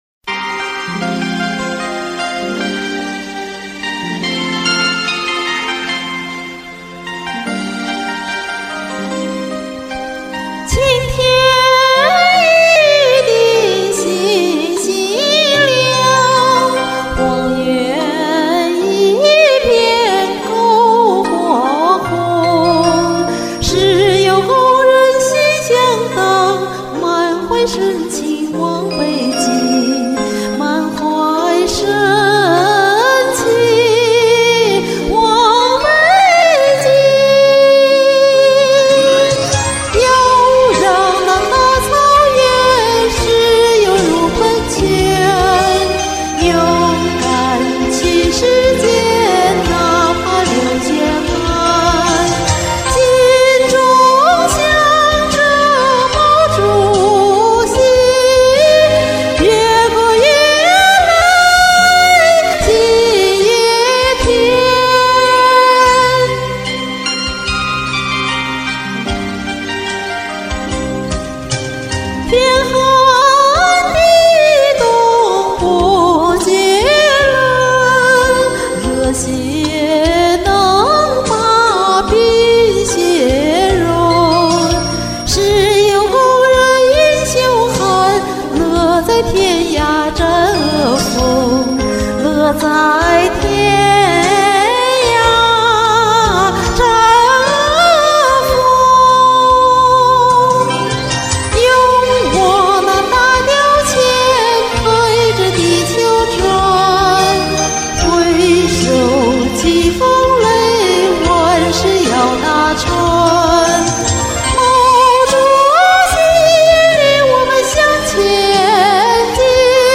以前录了都没仔细听，这次发现：怎么好几处都喷麦？
后来我一查：是这伴奏太糟了，好几处有“咚咚”的声音，尤其是第一段一开头那声“咚”。
声音很清亮，充满激情，真棒！